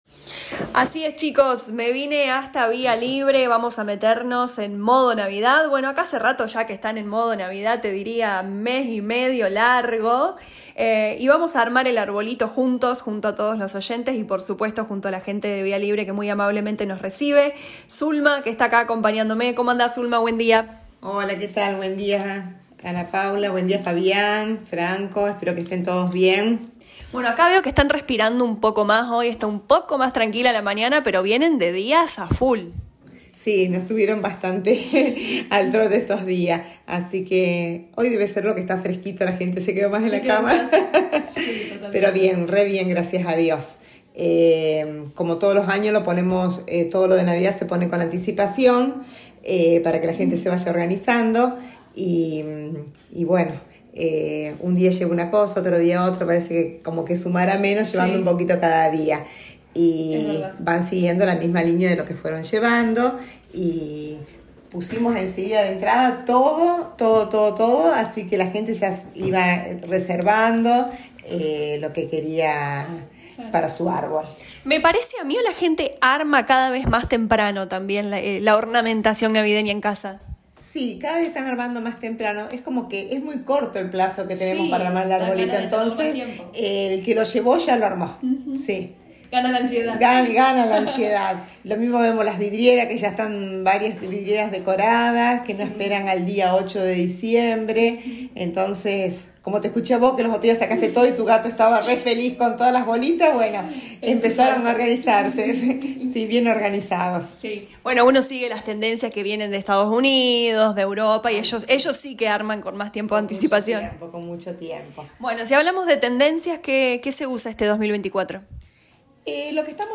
Como todos los años, LA RADIO 102.9 FM visita Vía Libre para armar juntos el arbolito de Navidad y conocer las tendencias de este año en luces, colores, adornos, etc.